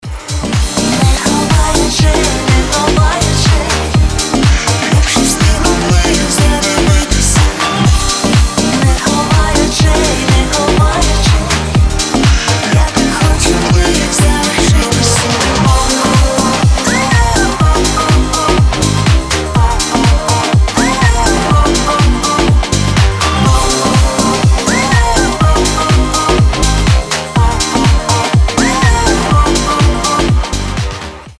• Качество: 320, Stereo
громкие
Club House
future house